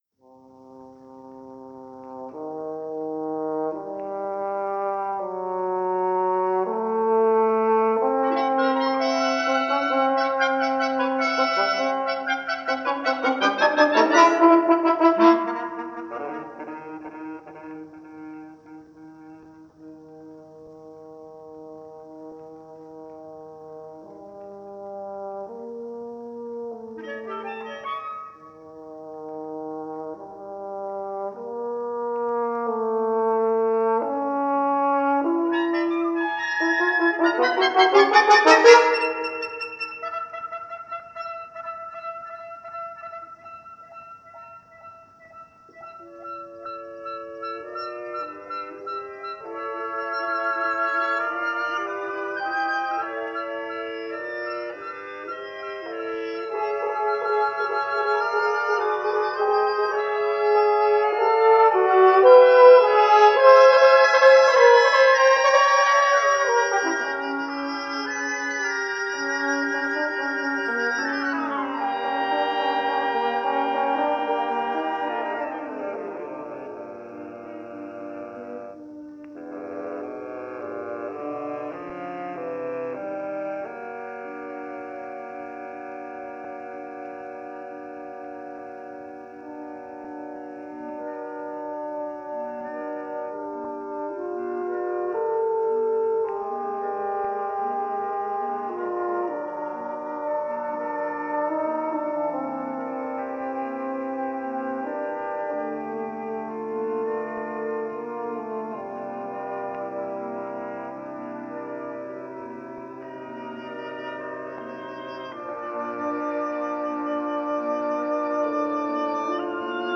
Paris Studios